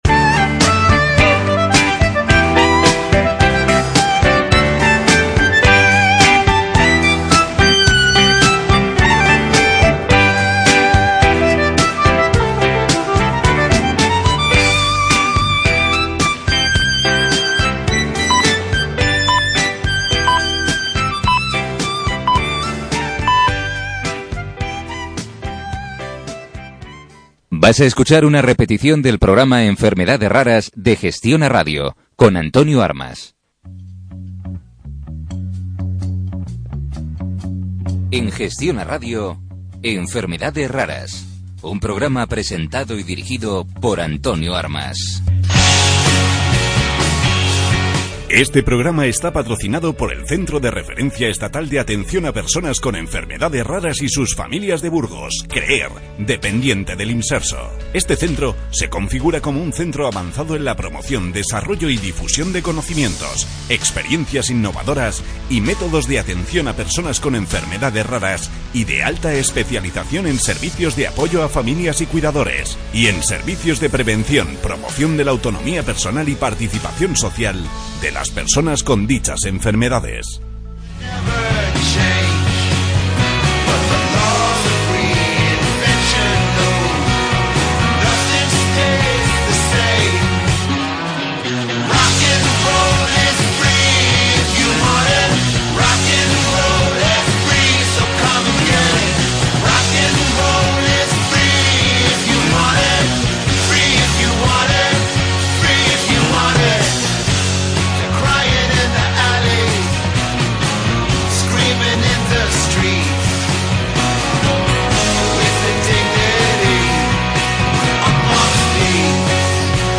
El programa de radio ‘Enfermedades Raras’ del 3 de marzo de 2016